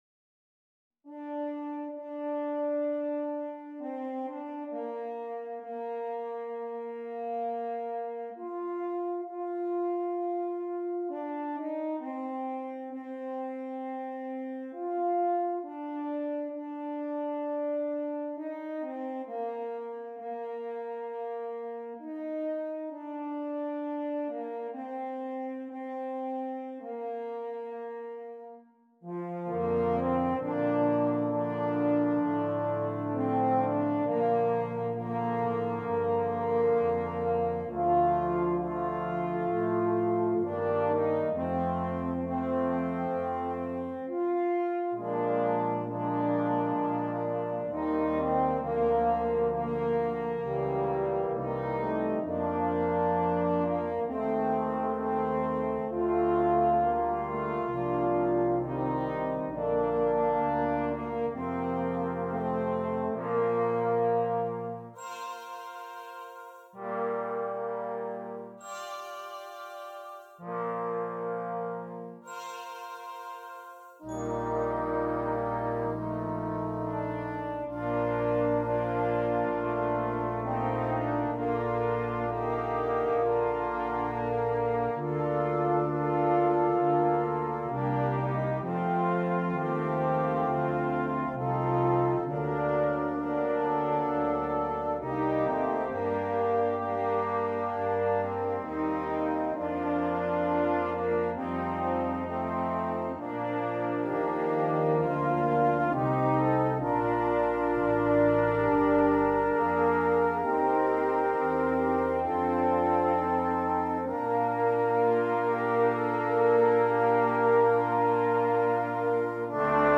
Brass Choir (4.4.3.1.1.perc)